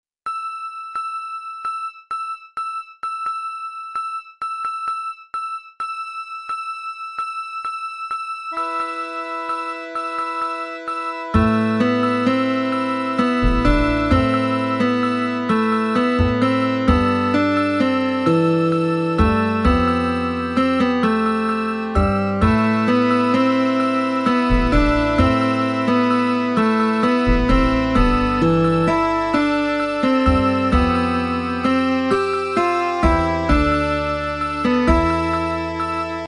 モールス符号系では、短点と長線との組み合わせでキャラクタ(ABC、数字、記号など)を表わす。
Morse.mp3